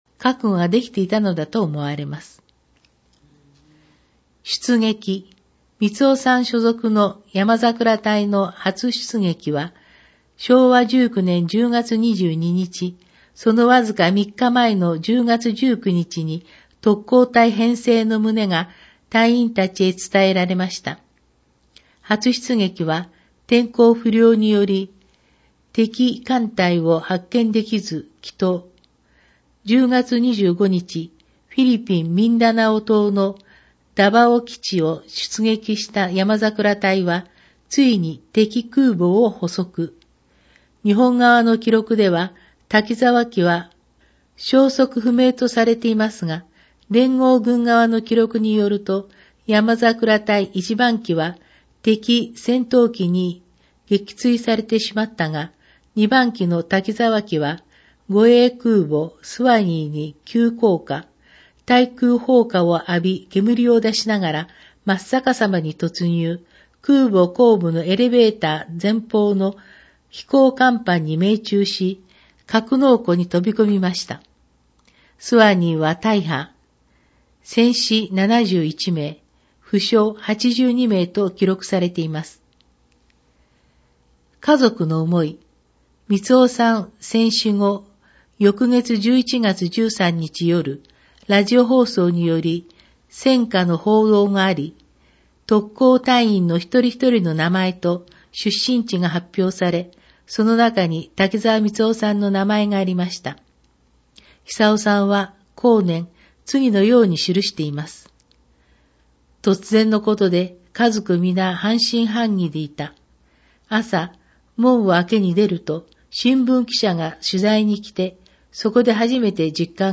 音訳版ダウンロード(制作：おとわの会）